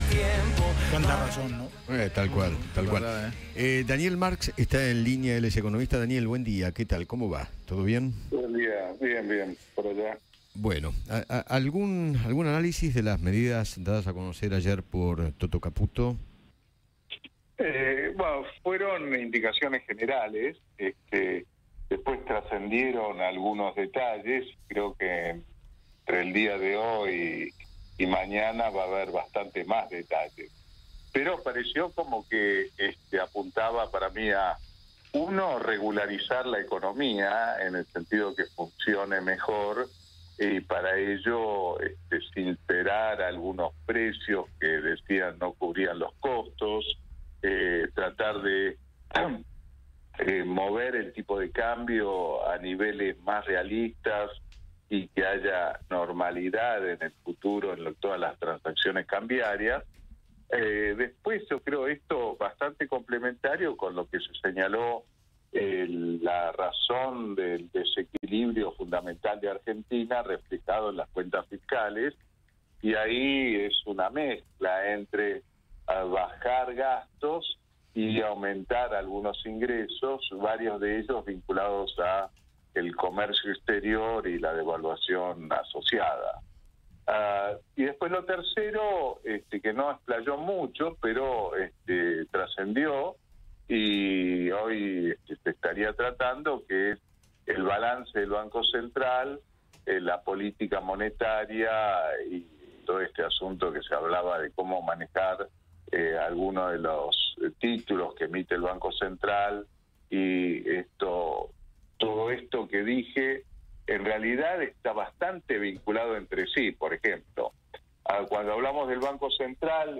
Eduardo Feinmann conversó con el economista Daniel Marx sobre las medidas económicas que dio a conocer Luis Cuputo.